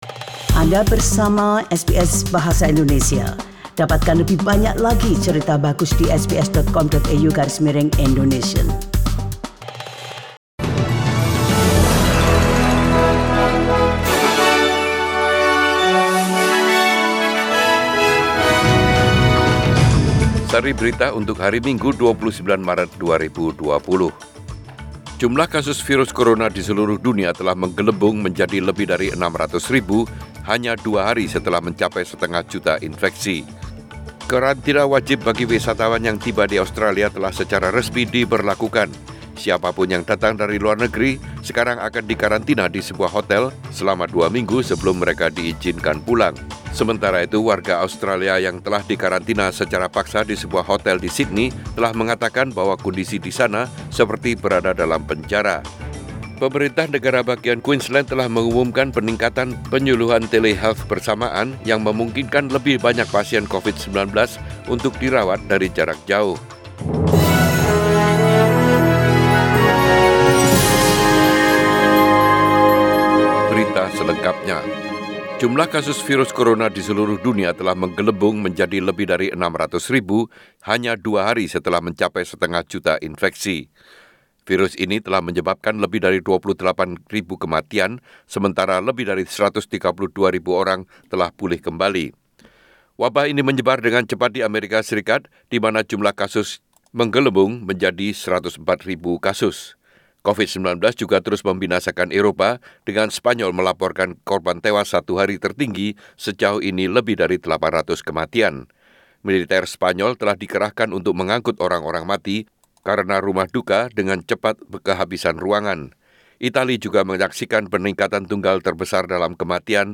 SBS Radio News in Bahasa Indonesia - 29 March 2020